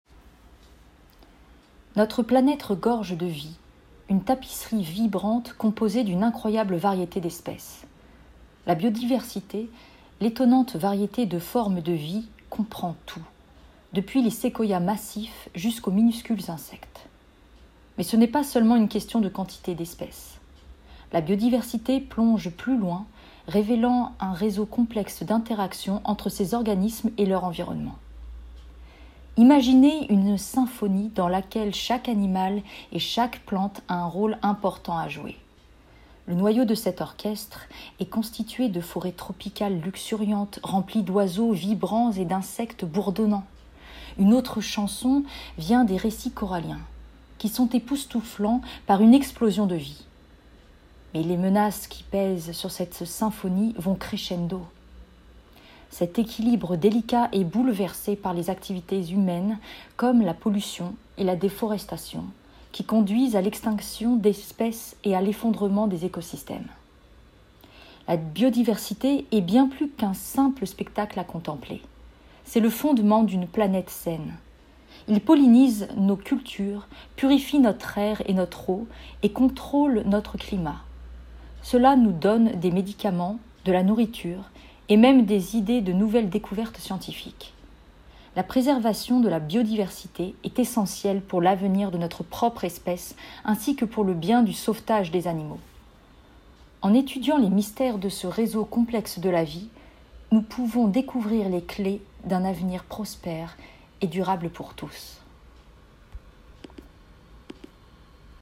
Essai voix-off
- Mezzo-soprano